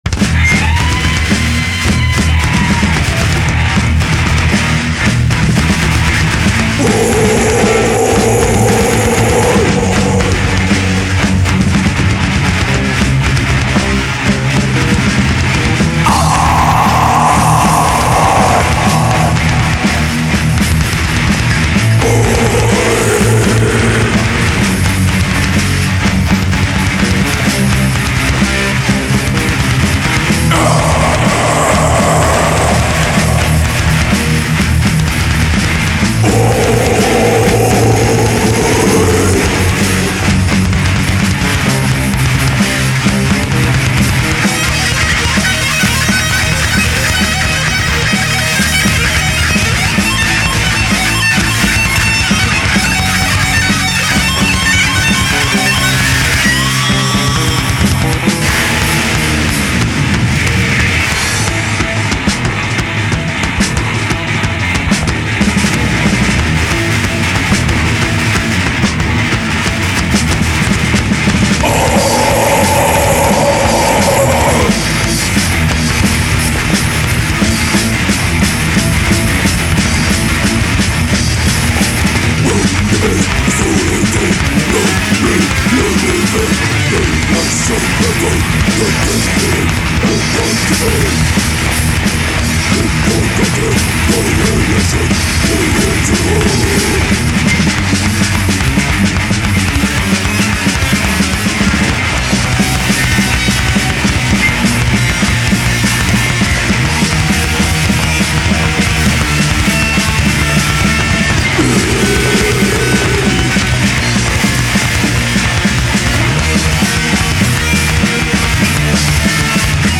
thrash / death metal France